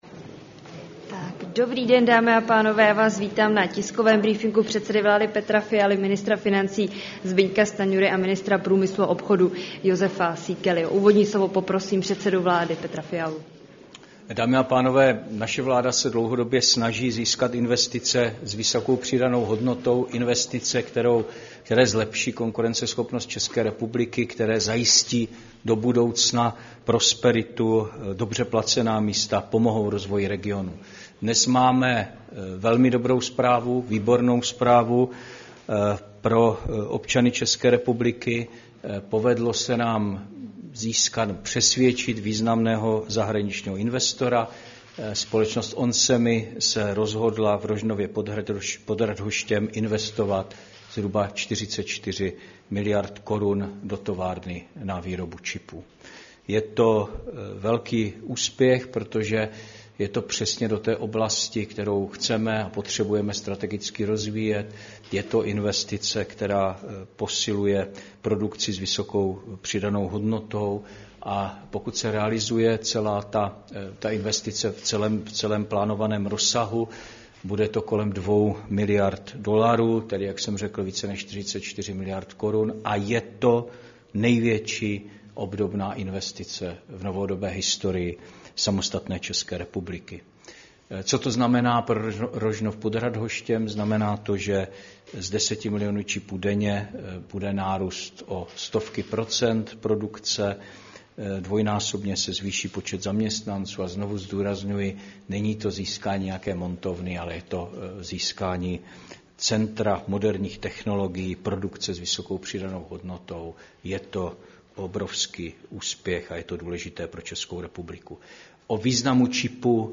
Tisková konference k oznámení rekordní investice firmy onsemi v Rožnově pod Radhoštěm, 19. června 2024